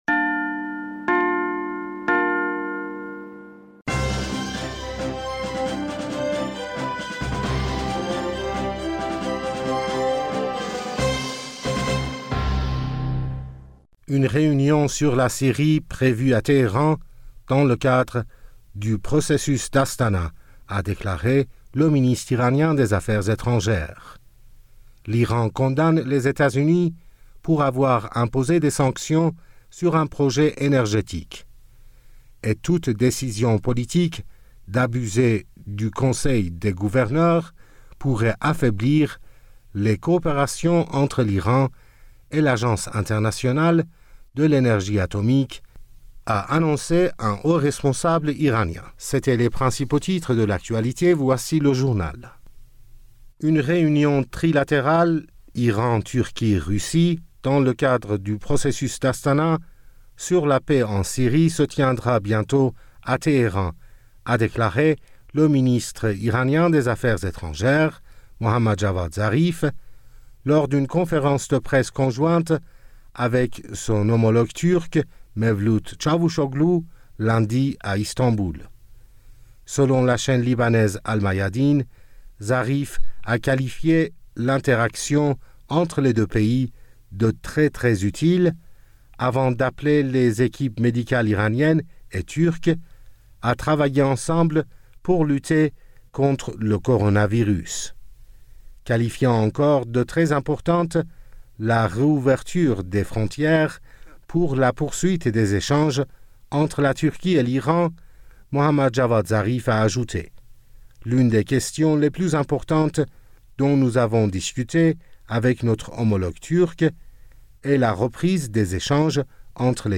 Bulletin d'information du 16 Juin 2020